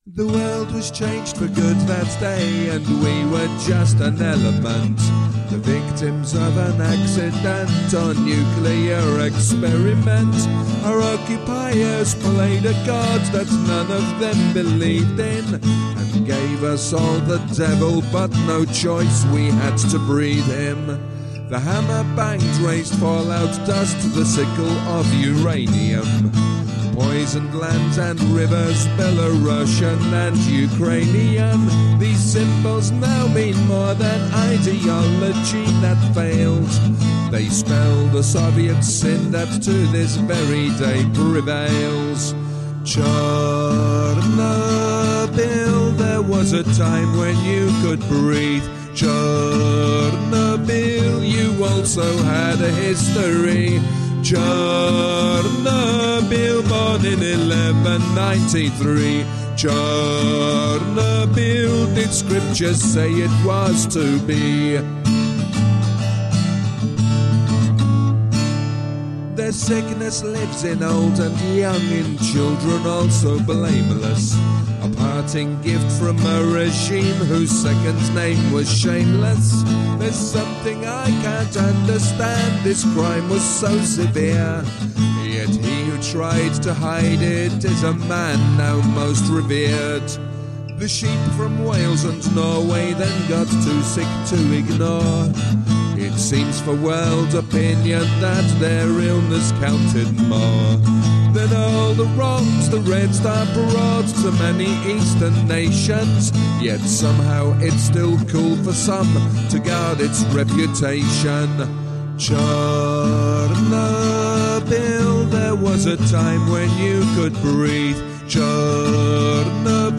Genre : Ukrainian